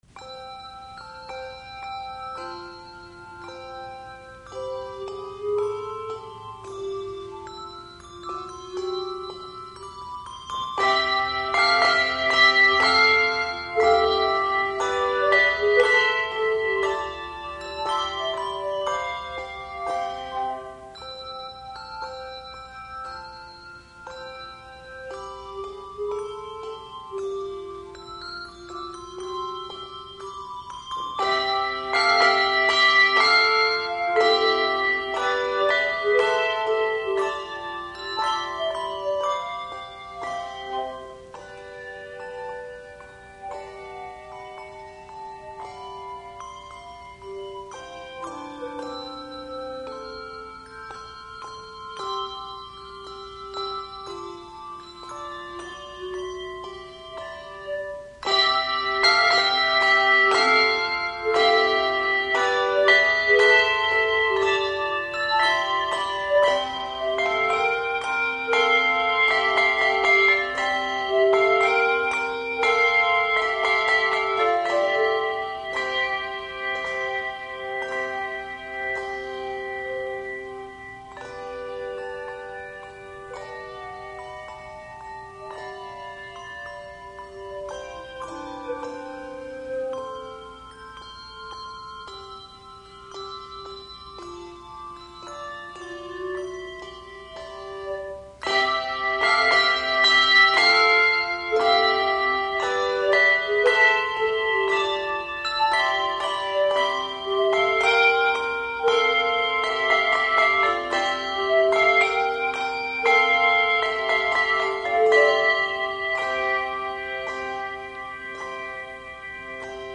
Handbell Quartet
Genre Sacred